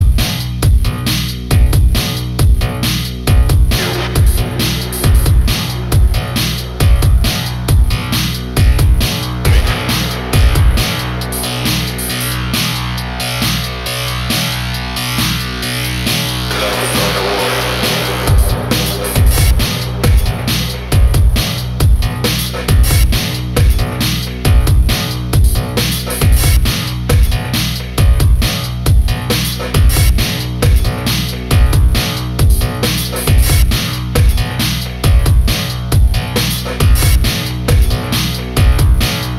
Stereo
Electronic , Techno